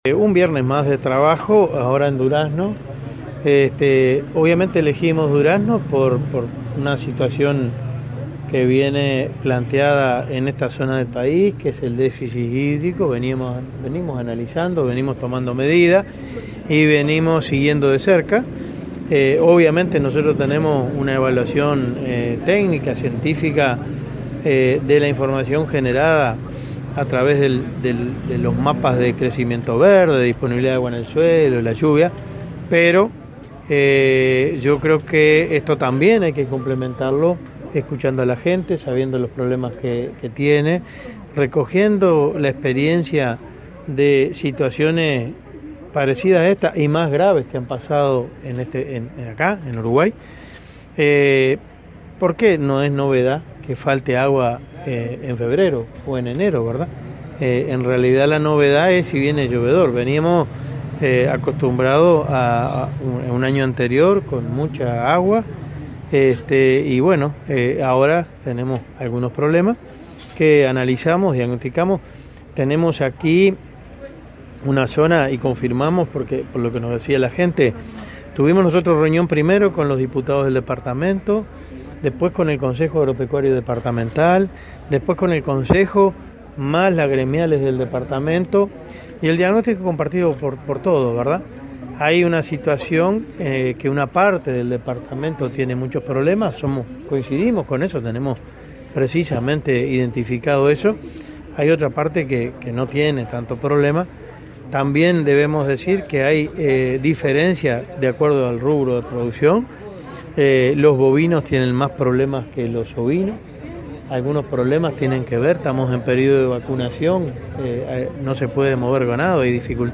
“El Gobierno invirtió US$ 2 millones en 263 intervenciones para afrontar el déficit hídrico en Durazno”, subrayó el ministro de Ganadería, Enzo Benech, al visitar el departamento como parte del diálogo directo con productores. Dijo a la prensa que irá a un establecimiento que accedió a estos beneficios y hoy está preparado para enfrentar el déficit hídrico.